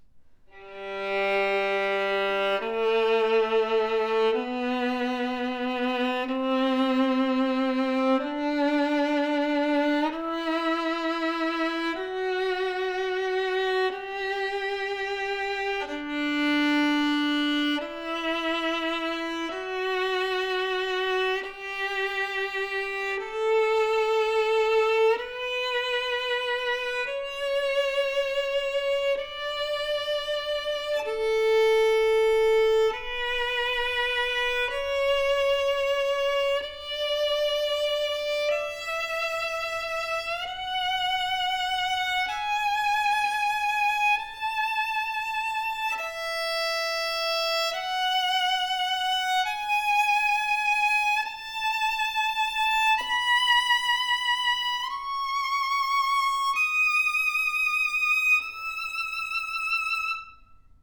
A RARE antique version of “Messiah” Stradivarius that sings with extraordinary WARM DEEP OPEN TONE, vibrant strad tone with surprising deep lower register. 20 yrs old Alps/Italian spruce and exquisite European maple that attributes to the original “Messiah” Stradivarius, finest tonewood usage/selection at this price range!
Phenomenal resonance and projection that sings with a bold dimensional tone, highly projective voice and even across the strings! Easy to push while taking great bow pressure, speaks with great depth and projection as the audio performance clip represents.